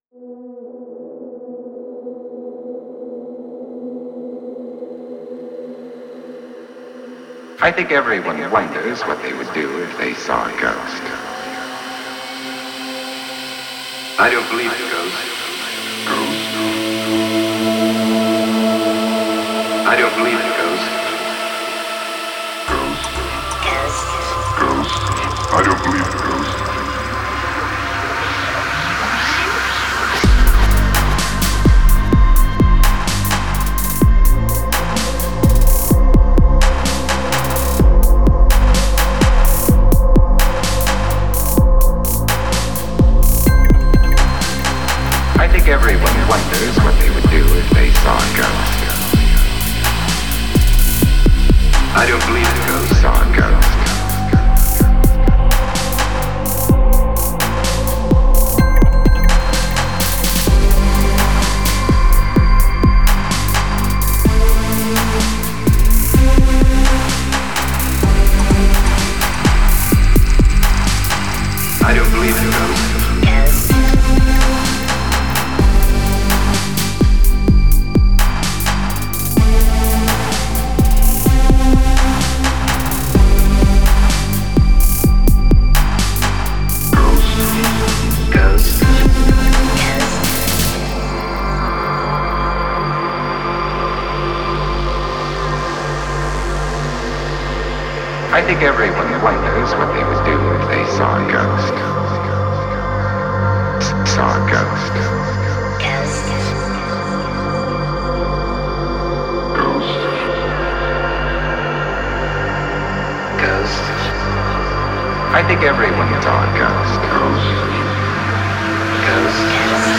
EBM, Industrial, Dark Electro, Cyberindustrial, dark techno